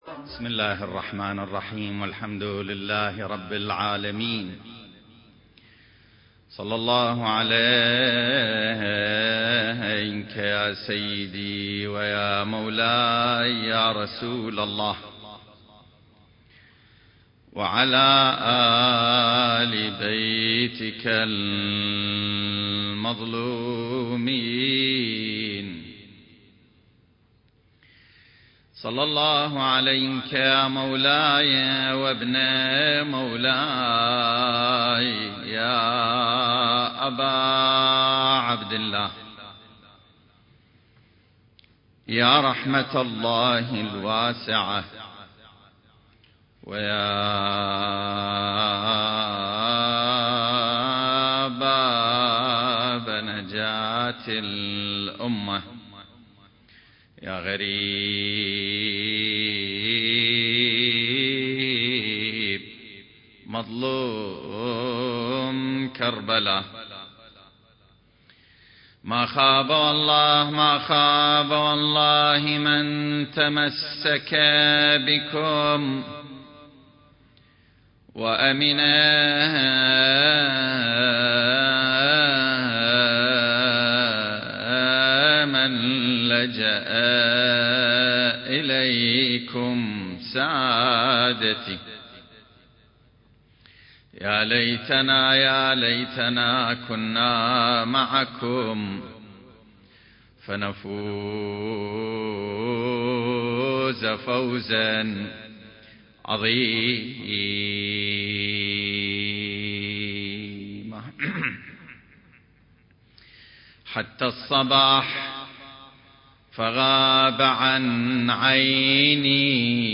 سلسلة محاضرات: نفحات منبرية في السيرة المهدوية المكان